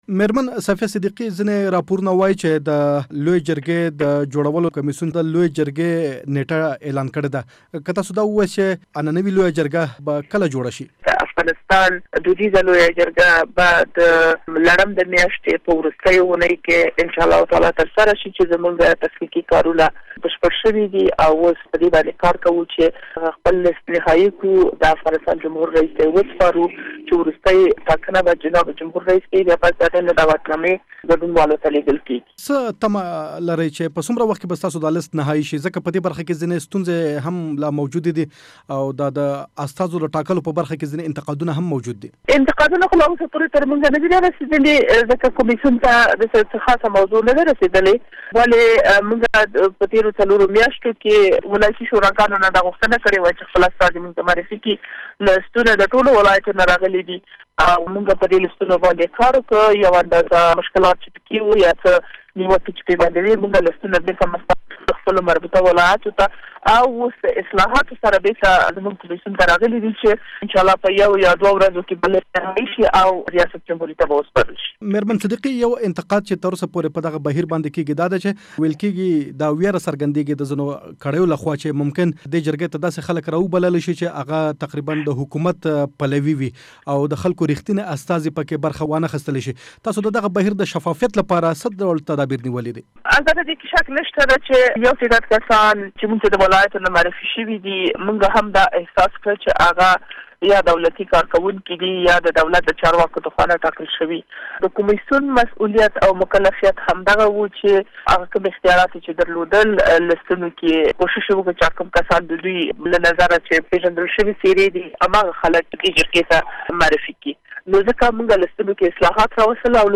له صفیه صدیقي سره مرکه